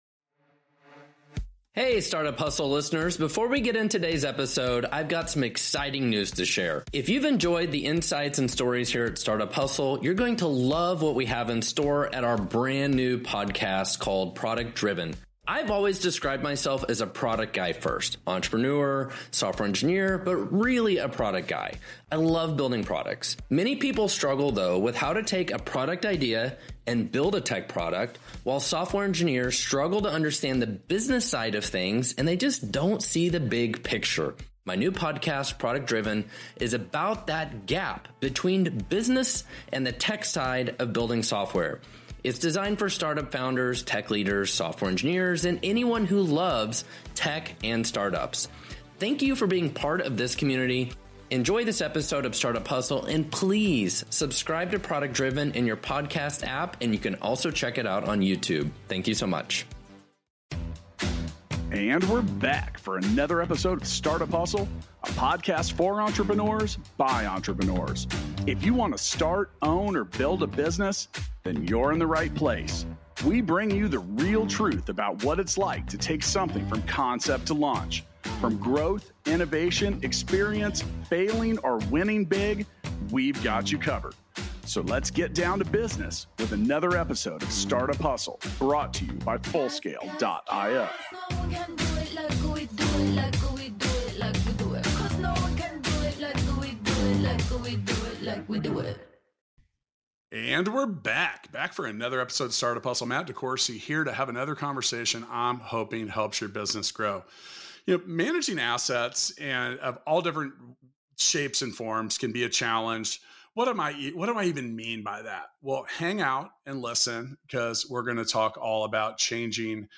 Enjoy a founder-to-founder conversation about disrupting an industry and how fighting the 8,000-pound gorilla can be worthwhile and lucrative.